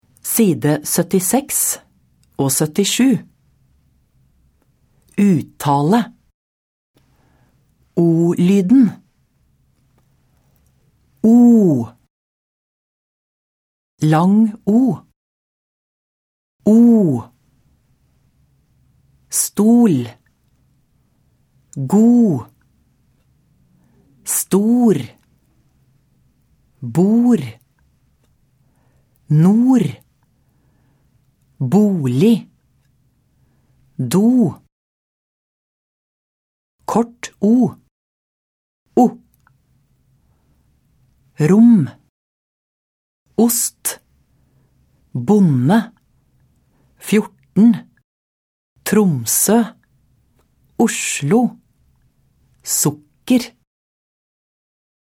Uttale: O-lyden (s. 76-77)